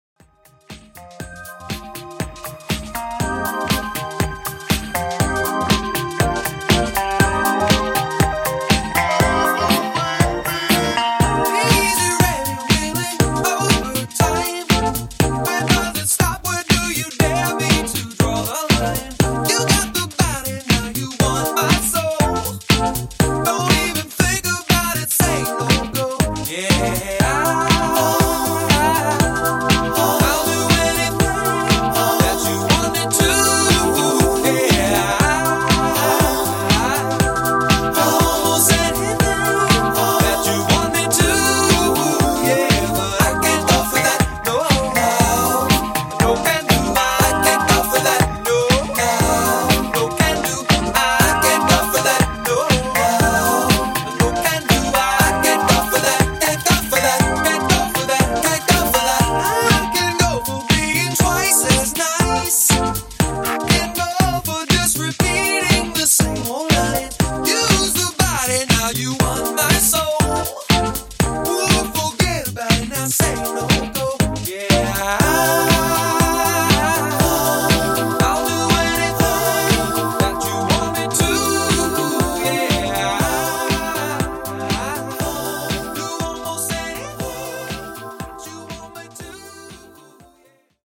80s Classic Mix)Date Added